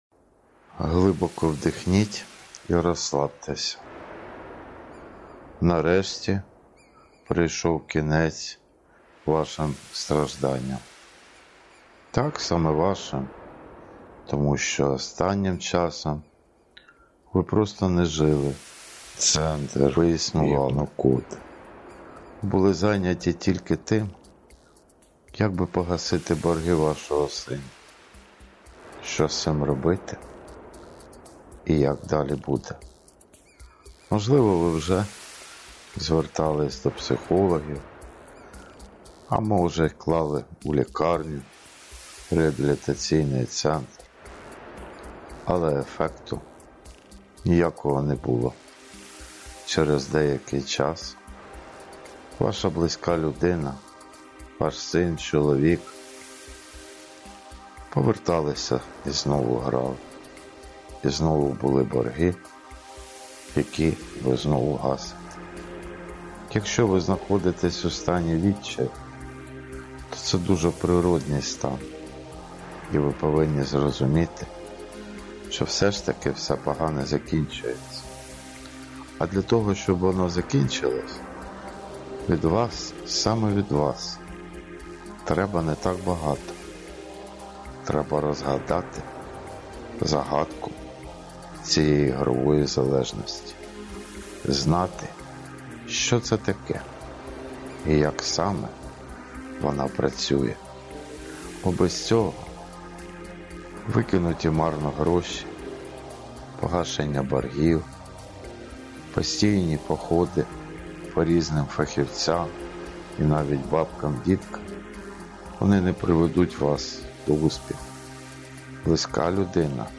Звернення психотерапевта до батьків ігроманів
Слово психотерапевта